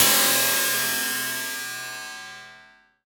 Index of /90_sSampleCDs/Optical Media International - Sonic Images Library/SI2_SI FX Vol 7/SI2_FX Menu 7
SI2 CRASH06R.wav